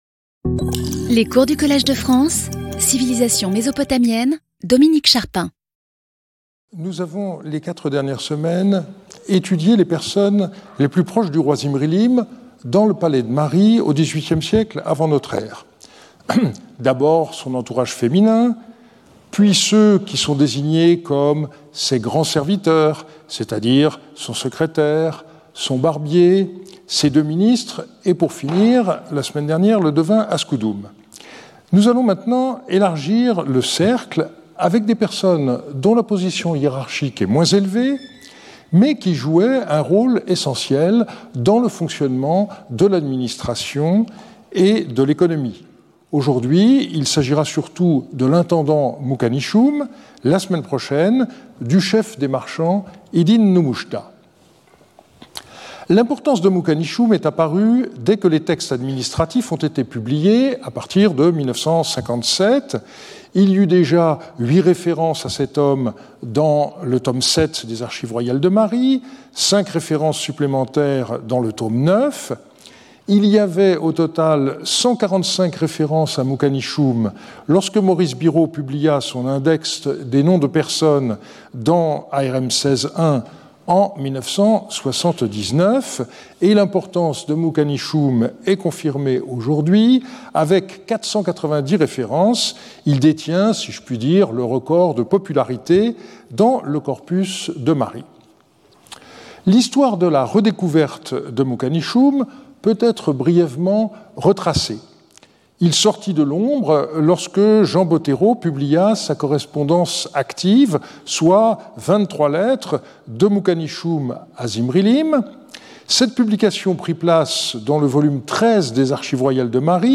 Intervenant(s) Dominique Charpin Professeur du Collège de France Événements Précédent Cours 13 Jan 2025 11:00 à 12:00 Dominique Charpin Introduction : Hammu-rabi, destructeur de Mari et Parrot, inventeur de Mari …